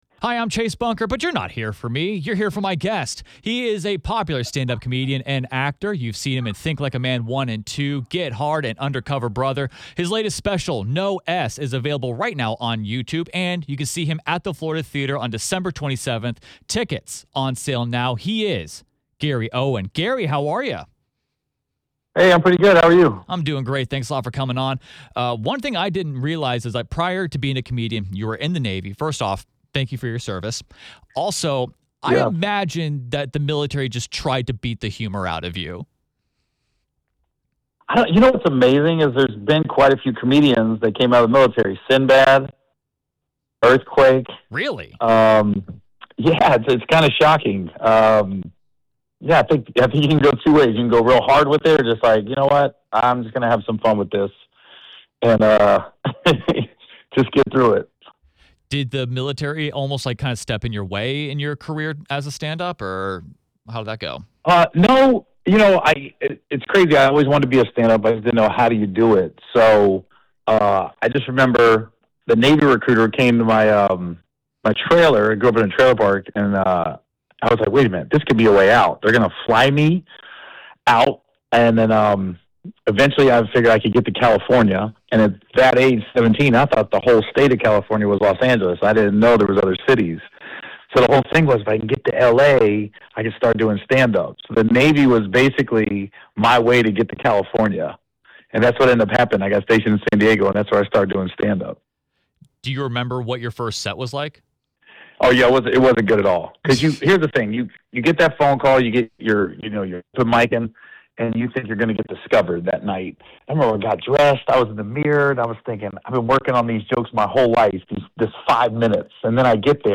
You can hear Owen reminisce about the first time he did stand-up comedy, where he went to do comedy, and redeem himself on a viral clip with comedian Andrew Schulz in the full interview.